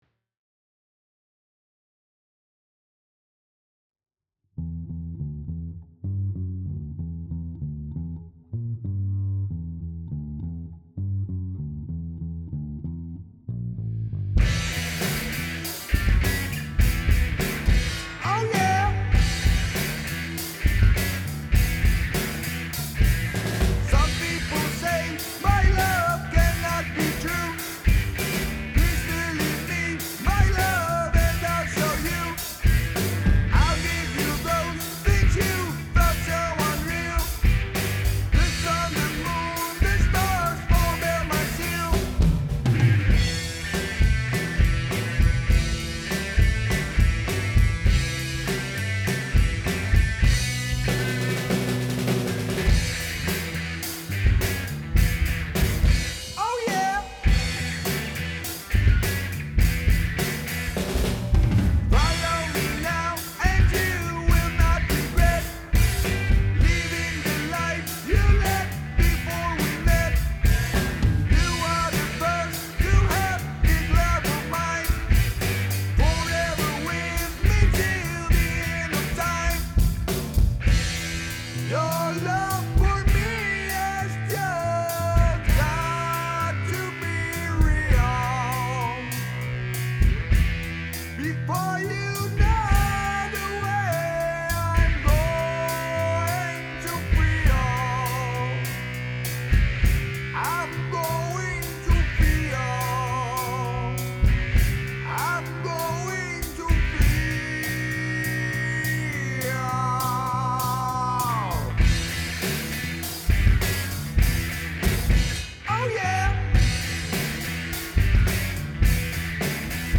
real raw hardrock